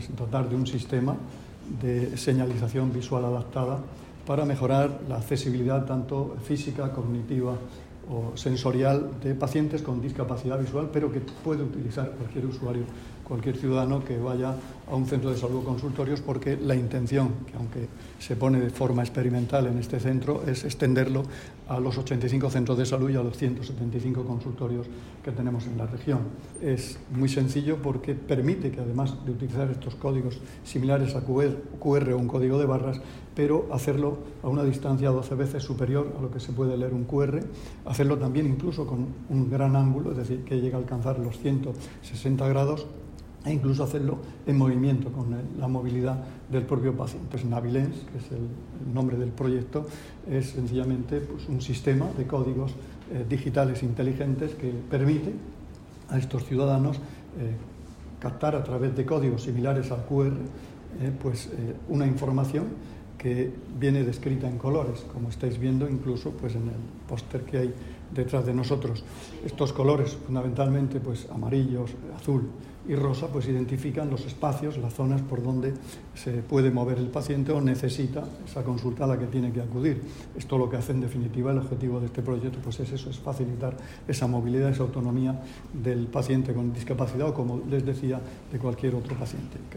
Declaraciones del consejero de Salud sobre el proyecto Navilens para mejorar la accesibilidad de los pacientes con discapacidad visual en los centros de salud [mp3}